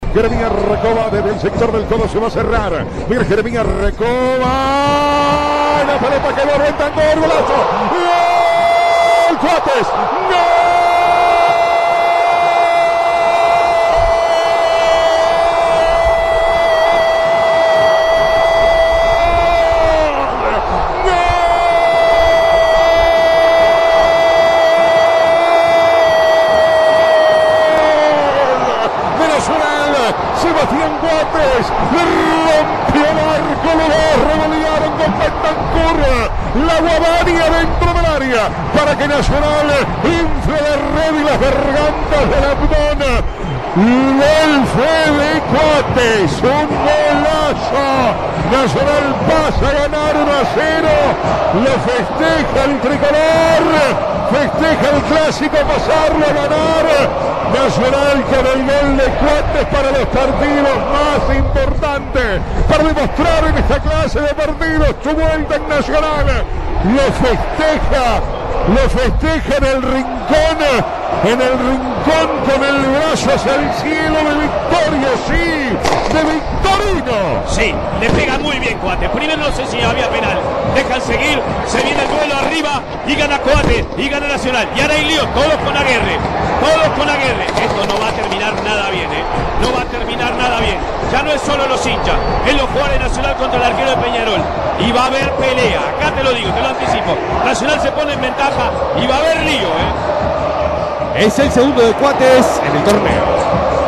El vibrante triunfo tricolor en la voz del equipo de Vamos que Vamos